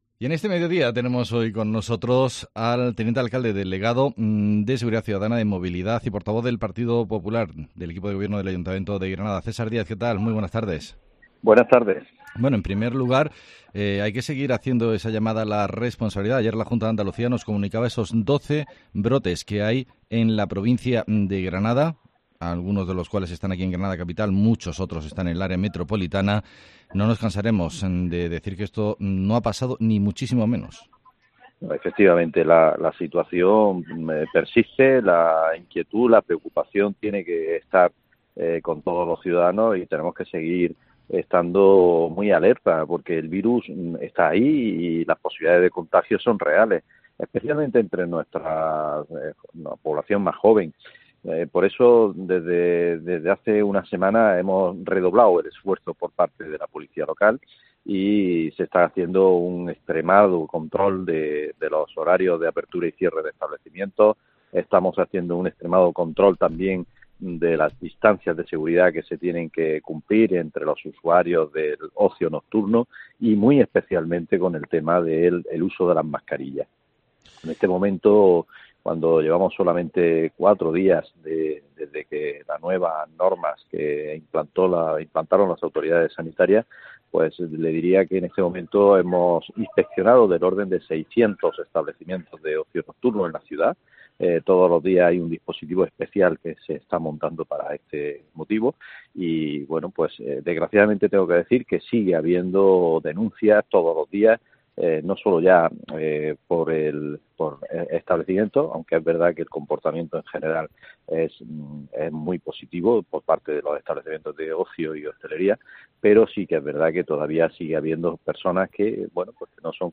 AUDIO: El concejal de seguridad ciudadana nos habla de la zona azul y la nueva movilidad